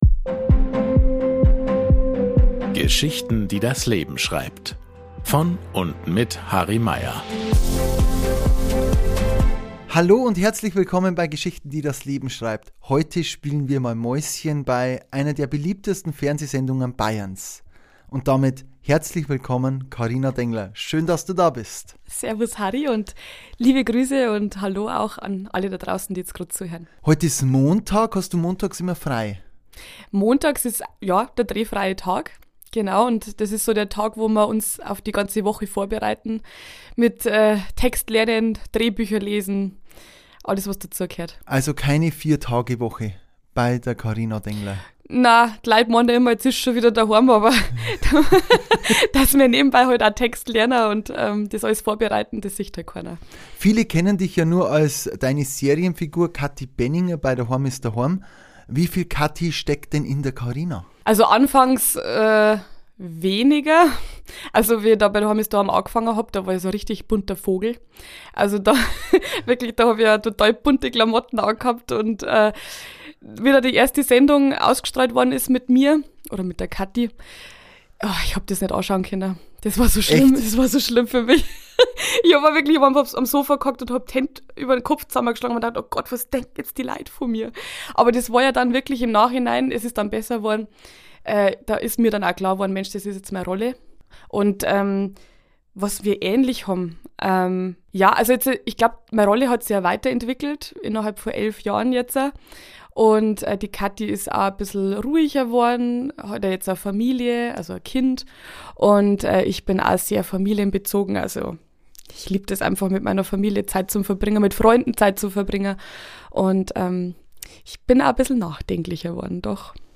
Ein Gespräch über Heimat, Dialekt und natürlich a bissl Dahoam is Dahoam.